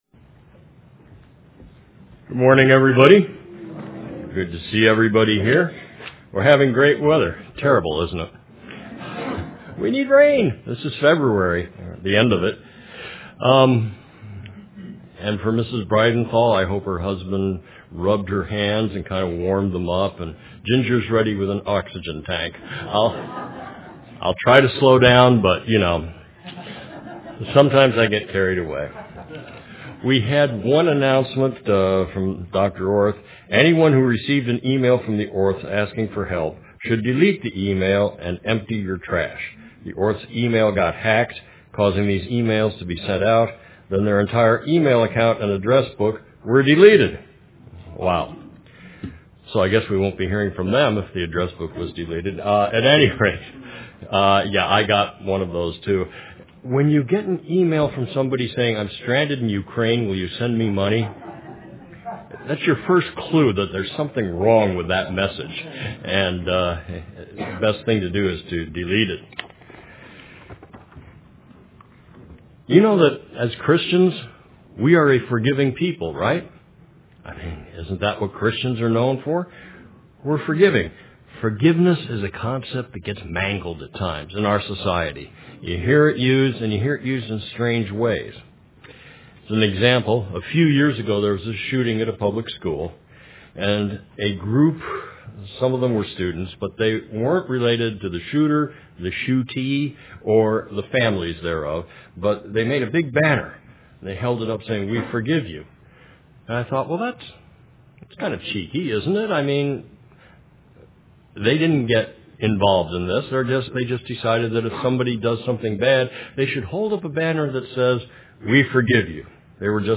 UCG Sermon Notes A partial list of Scriptures: Forgiveness is necessary for your health.